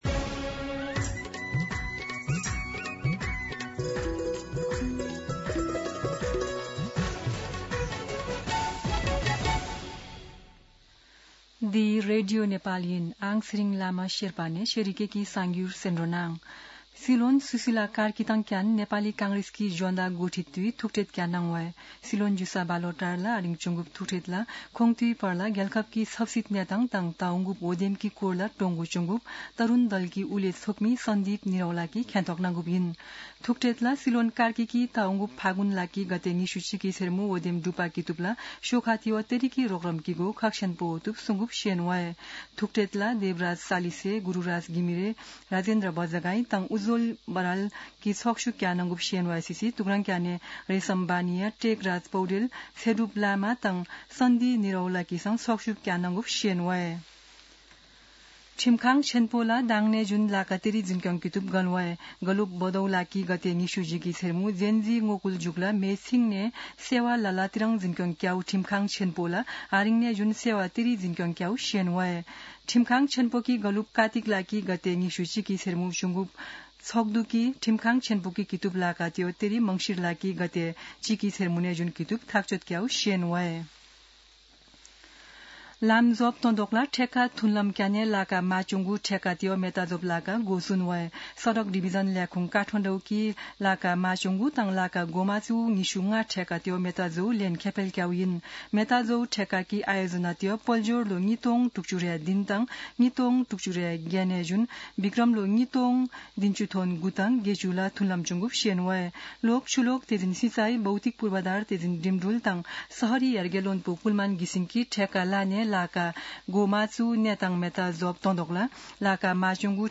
शेर्पा भाषाको समाचार : २ मंसिर , २०८२
Sherpa-News-8-2.mp3